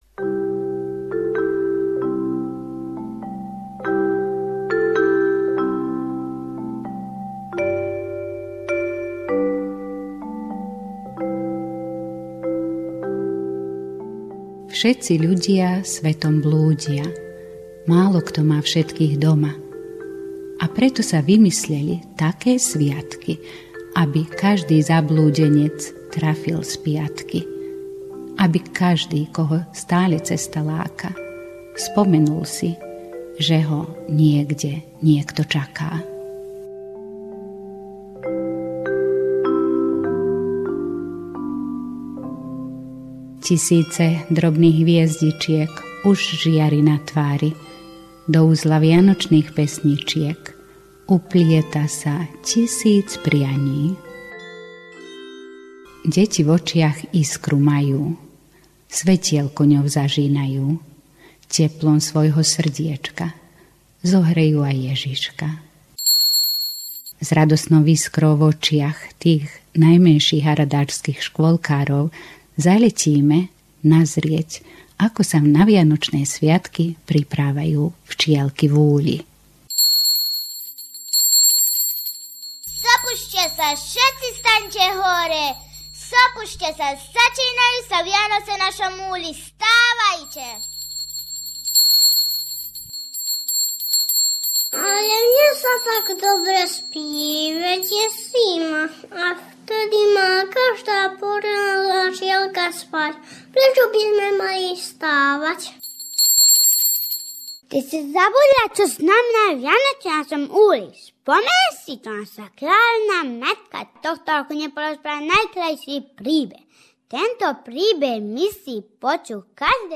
Zvuková pohľadnica